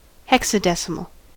hexadecimal: Wikimedia Commons US English Pronunciations
En-us-hexadecimal.WAV